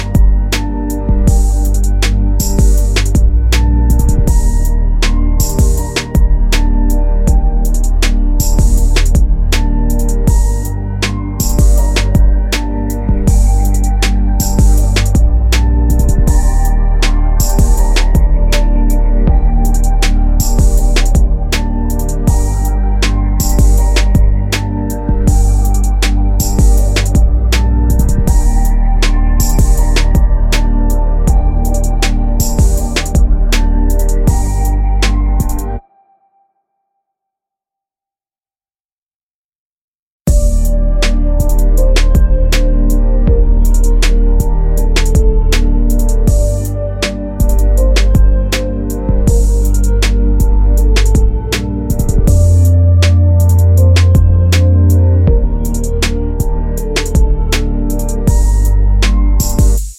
Explicit Version R'n'B / Hip Hop 3:39 Buy £1.50